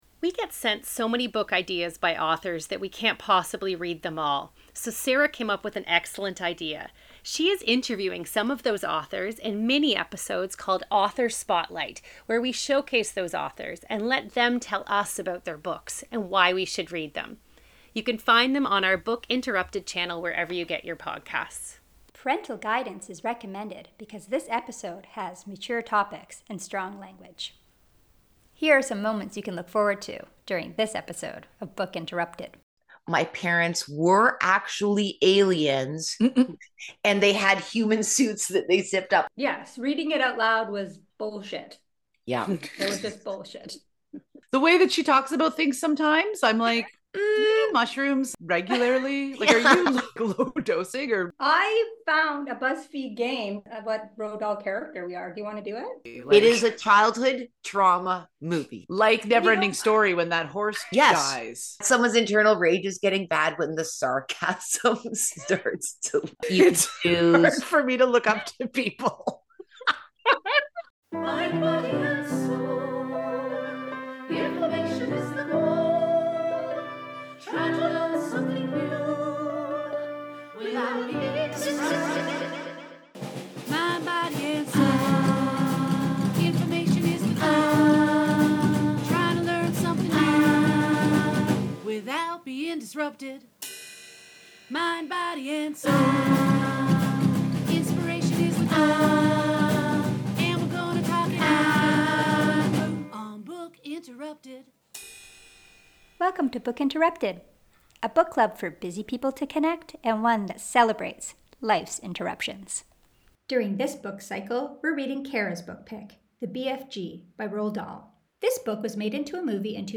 The women discuss the book, characters, and compare the novel to the feature film.